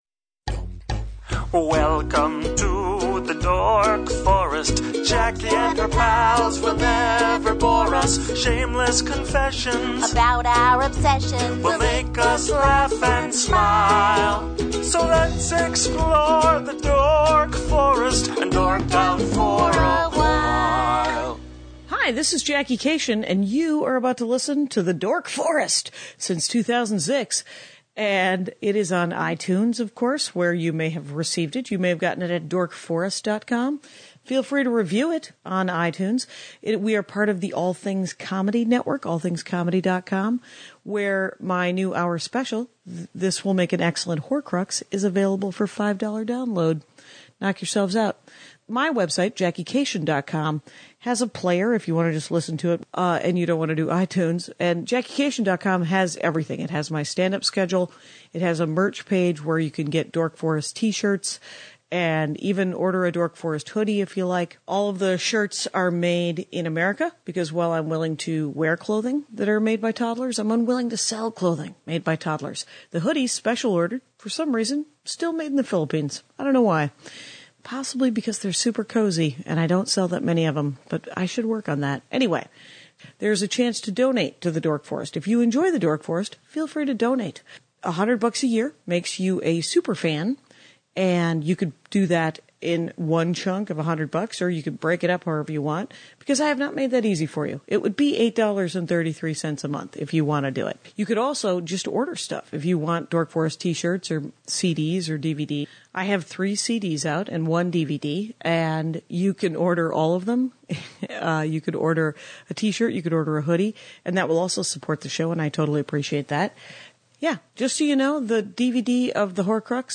But Manga, My Little Pony and all things tiny and adorable are discussed. So I think it’s worth going for, if only to hear me trying to keep it together with ONE mic.